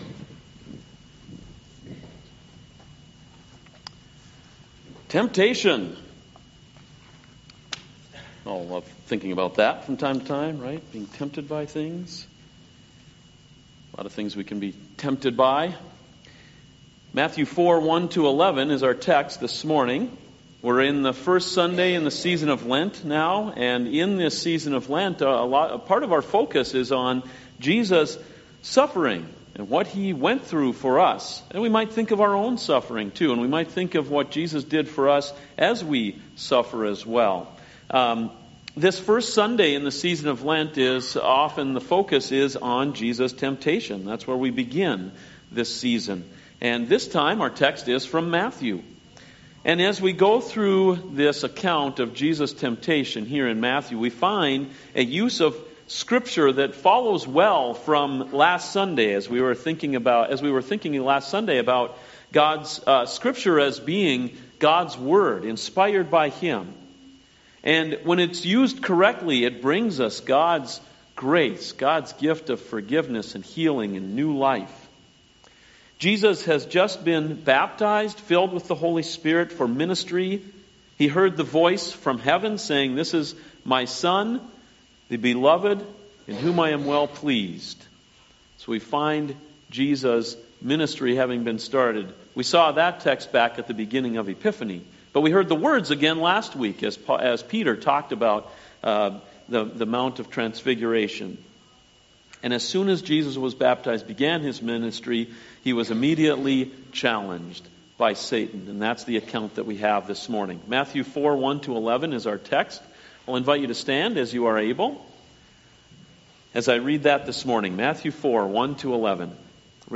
CoJ Sermons Temptation (Matthew 4:1-11)